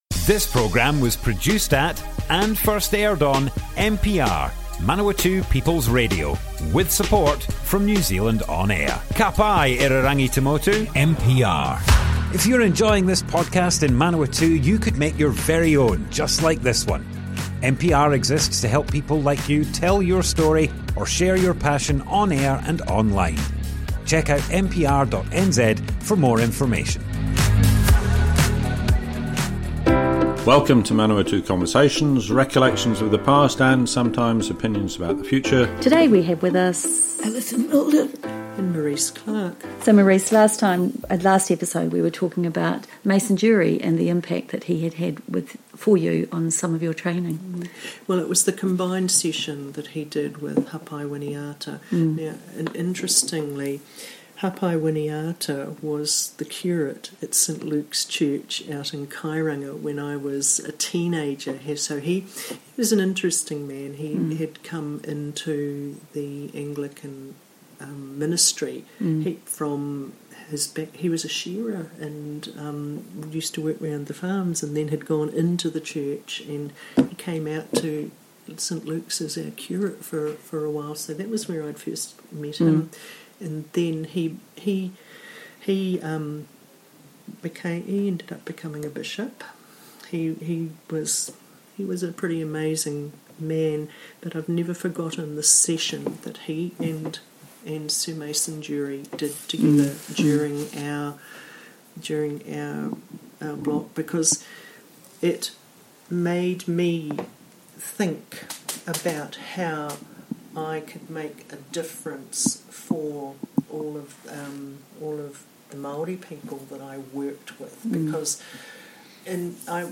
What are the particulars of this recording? Manawatu Conversations More Info → Description Broadcast on Manawatu People's Radio, 22nd October 2024.